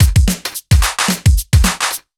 OTG_Kit 3_HeavySwing_110-A.wav